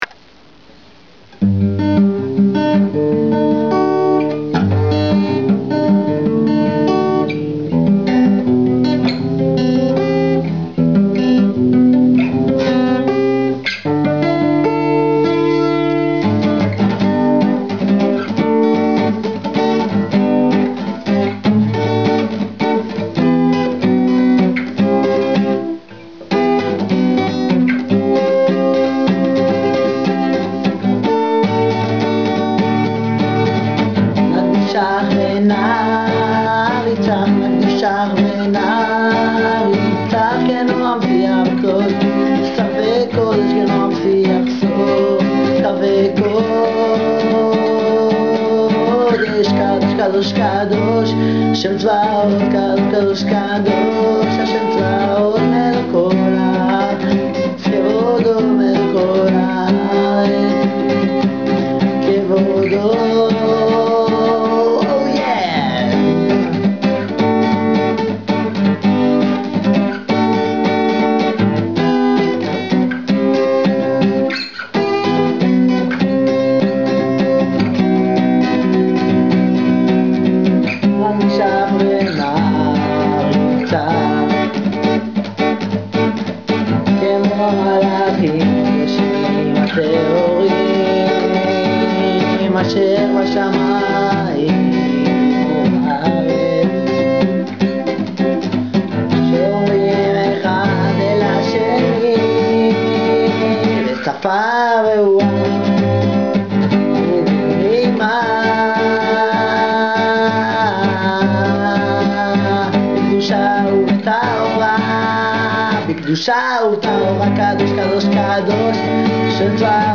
מצטער על הצרידות שנשארה מההרקדה בפורים
ג. קשה להבין את המילים. או שאתה לא שר ברור או שההקלטה לא טובה.
אחלה נגינה..למרות שכמו את המילים,לפעמים סוג של בלעת את הפריטות..